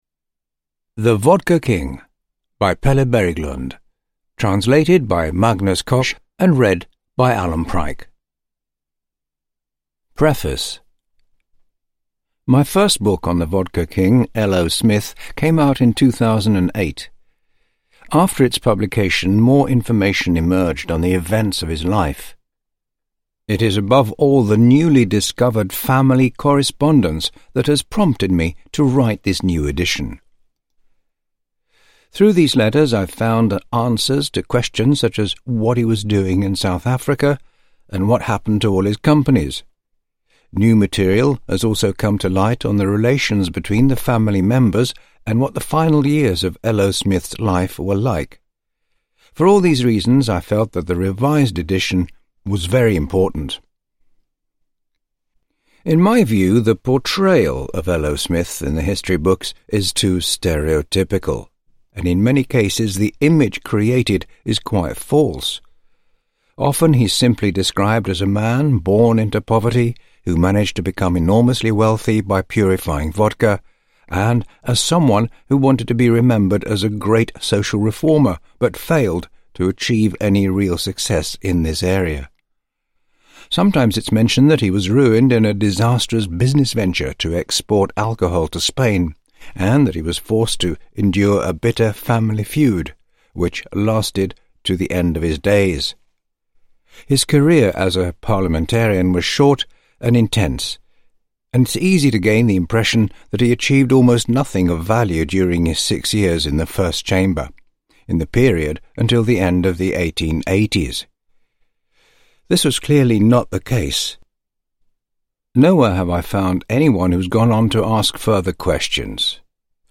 The Vodka King – Ljudbok – Laddas ner